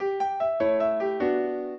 piano
minuet13-5.wav